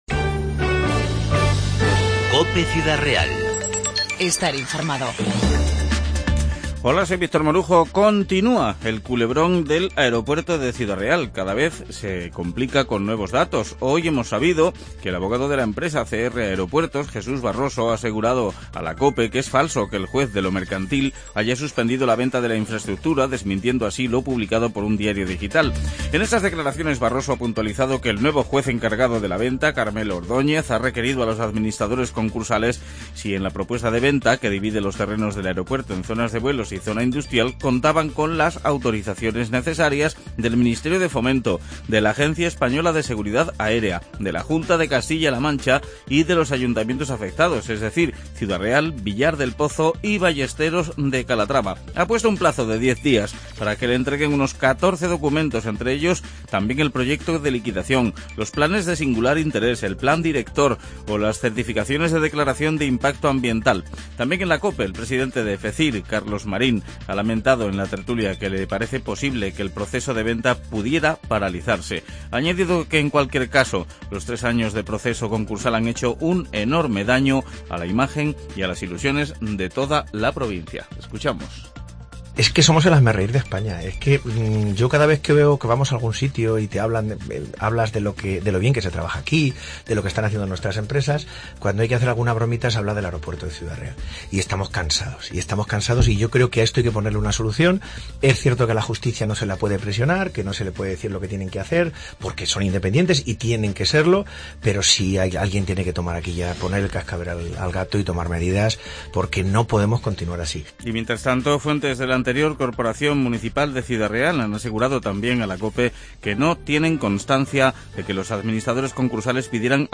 INFORMATIVO 4-11-15